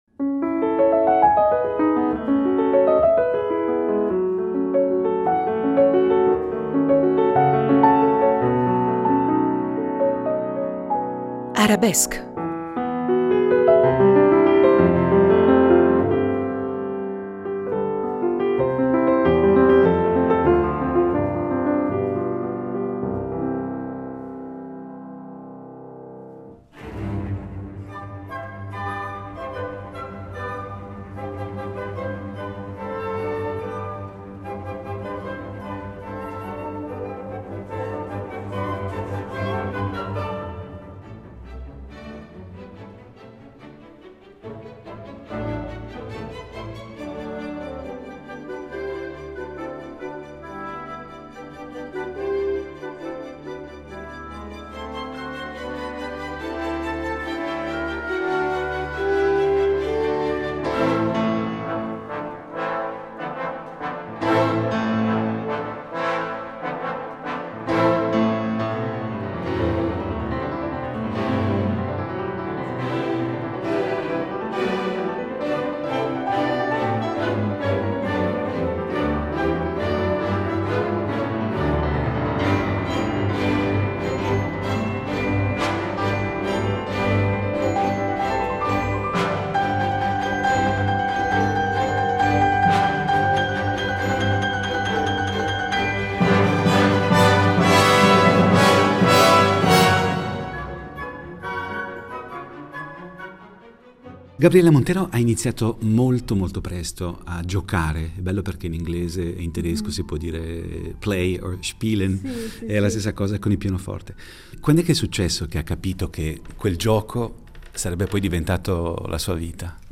pianista venezuelana